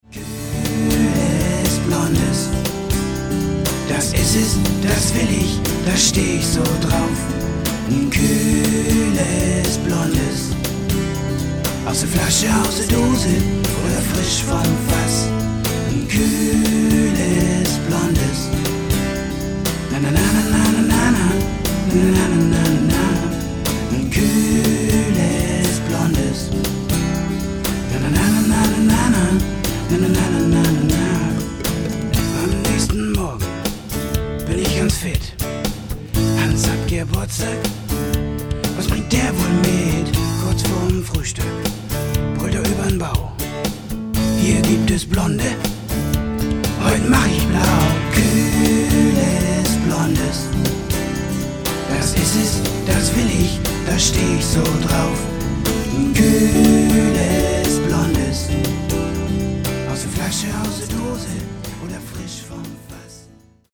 rockig-popige Hymne